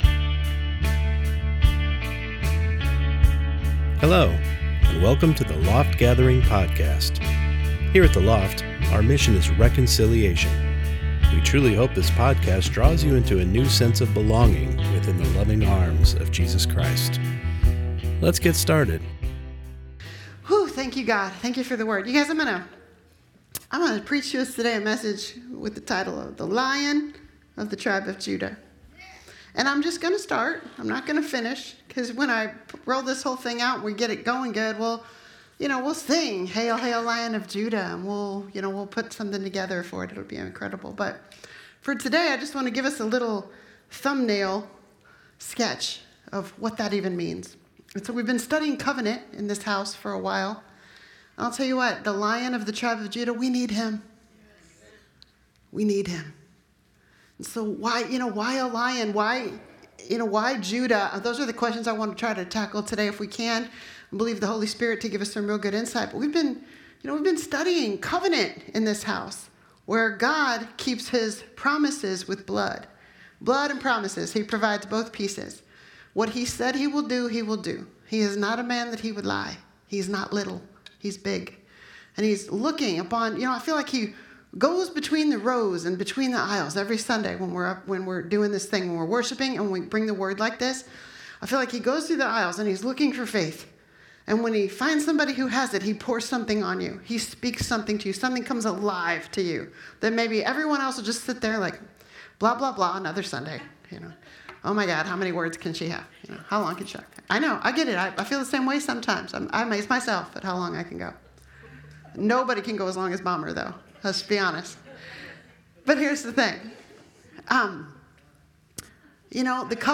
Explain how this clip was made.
Sunday Morning Service Service